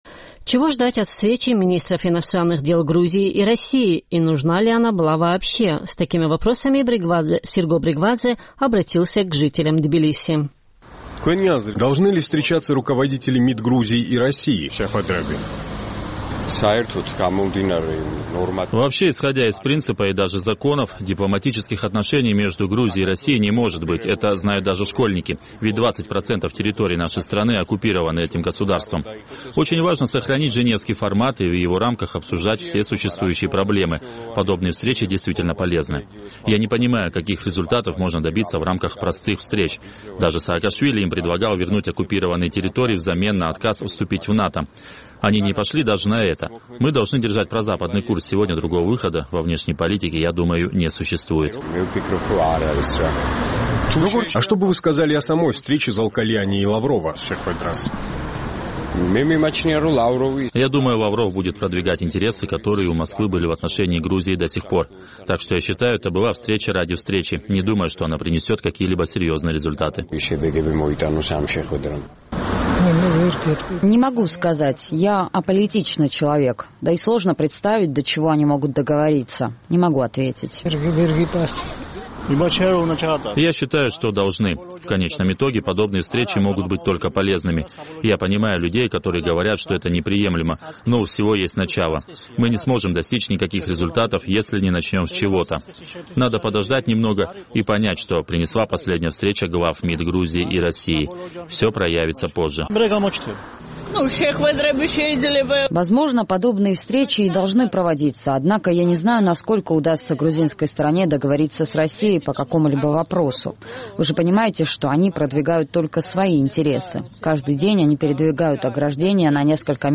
С такими вопросами мы обратились к жителям Тбилиси.